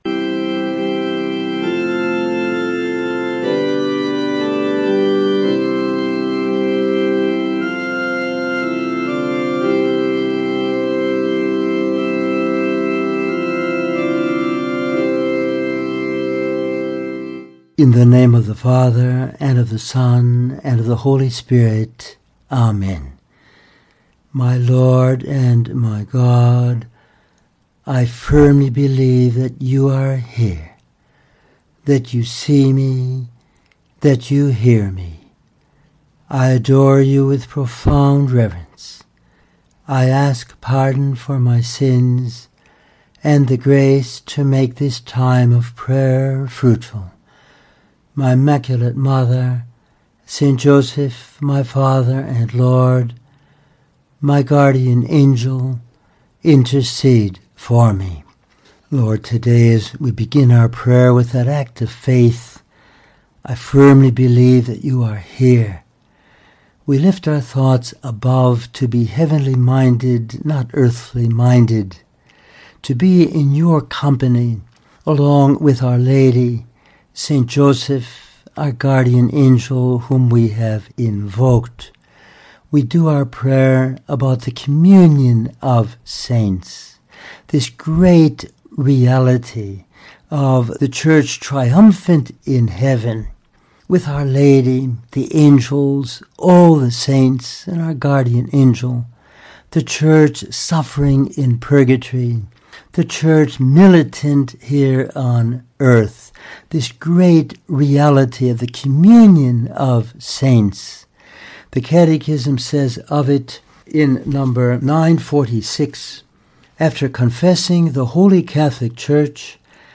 In this meditation we use texts of the Catechism of the Catholic Church, St Bernard, St Therese of Lisieux, St Josemaria, Pope Francis and Kimberly Hahn to pray about this union of the saints in heaven, the souls in purgatory and the faithful on earth, all helping one another on the way to heaven.